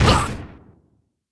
airship_die3.wav